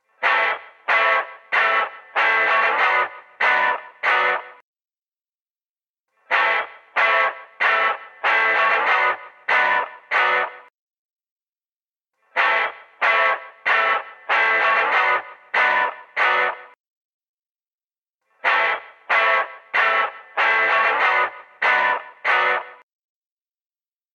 Recorded at Beware of Dog Studios - Chicago, IL 2011-2012